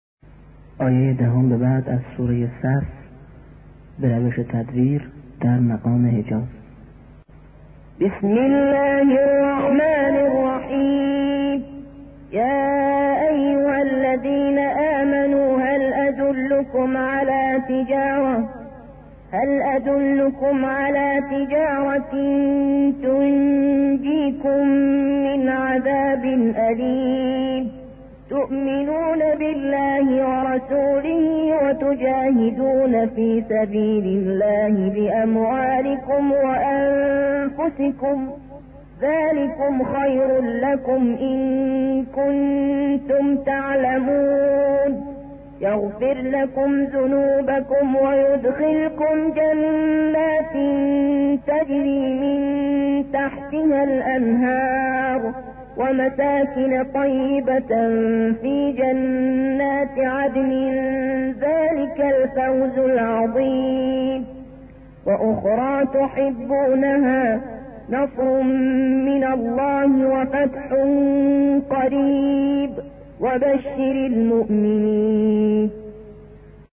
ترتیل در مقام حجاز
Hejaz-Tartil.mp3